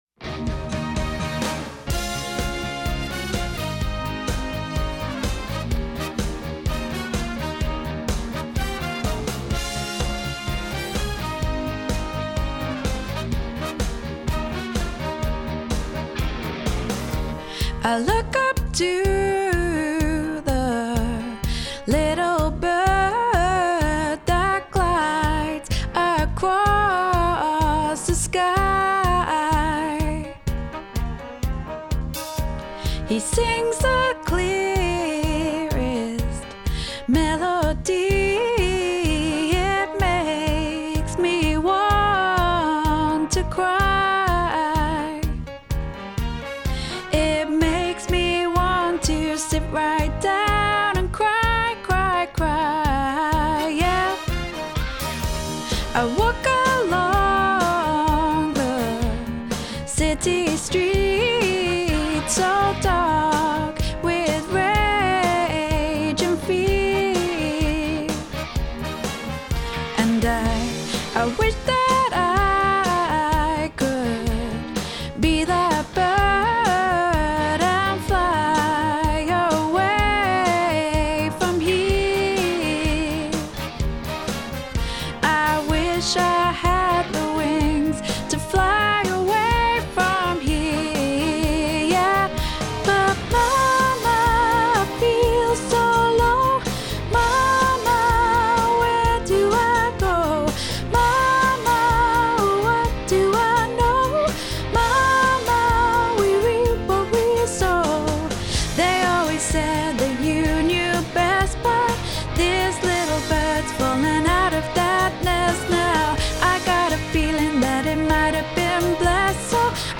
Probably best to learn this one by ear!) 3:18 Little Bird ALL 3:18 Little Bird SOPRANO 3:18 Little Bird ALTO 3:18 Little Bird TENOR 3:18 Little Bird BASS
Little+Bird+BASS.mp3